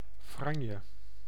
Ääntäminen
US
IPA : /ˈtæsəl/